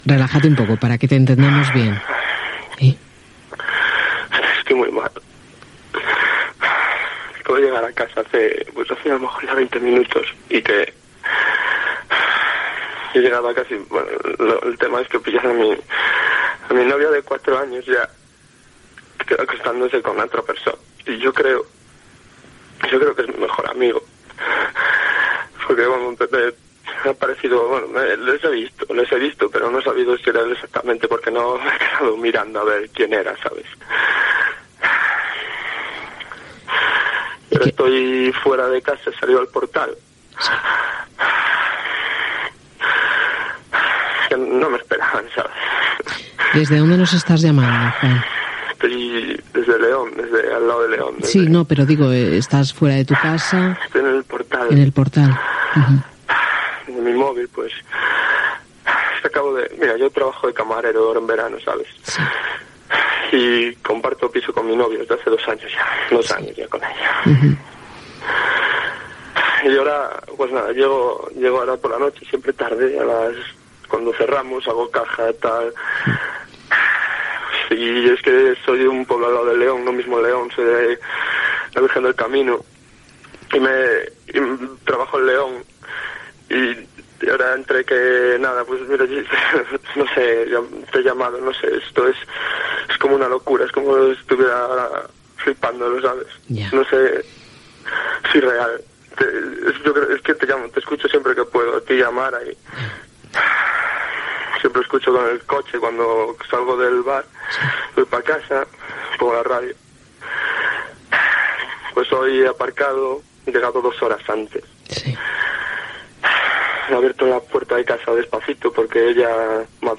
Trucada d'un oient enganyat per la seva nòvia.
Entreteniment